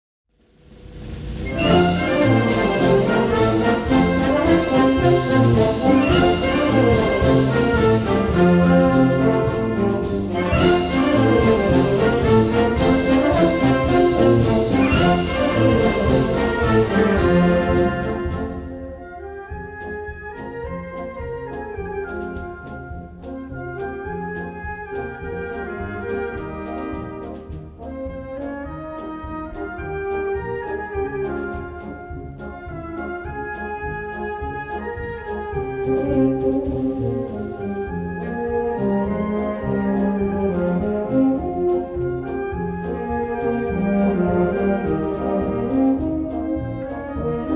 SYMPHONIC BAND
Paso-doble